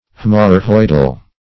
\H[ae]m"or*rhoid"al\
haemorrhoidal.mp3